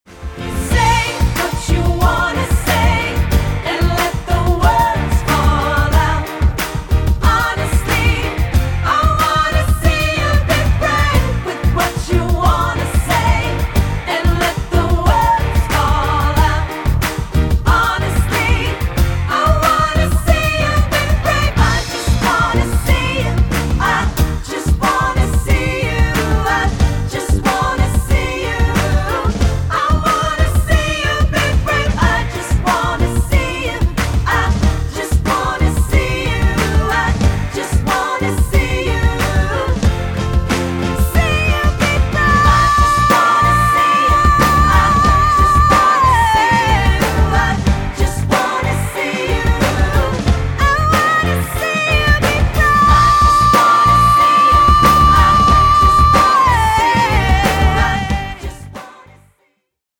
Choral Early 2000's Pop
SAB